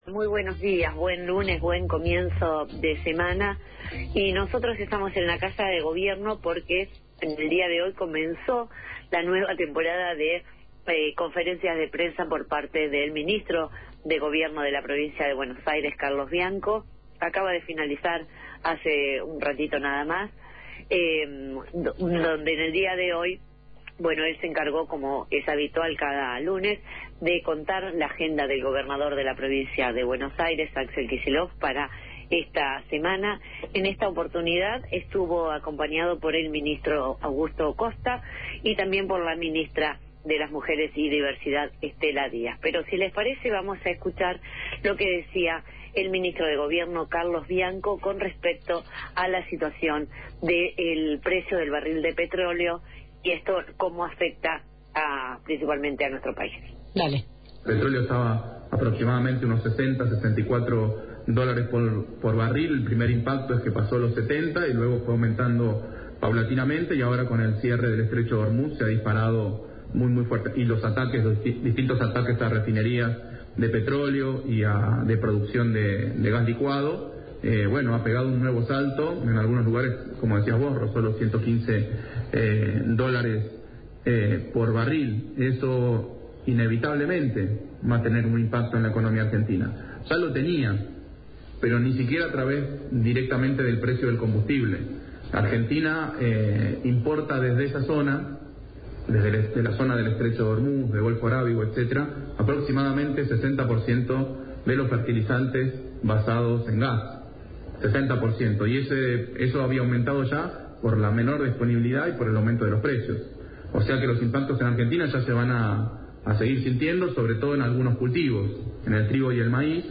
El ministro de Gobierno Carlos Bianco, durante la conferencia de prensa en la Casa de Gobierno de La Plata, junto al ministro de Producción, Augusto Costa, y la ministra de Mujeres y Diversidad, Estela Díaz, se mostró preocupado por el impacto en el valor de la cosecha por el incremento del valor del precio del barril de petróleo.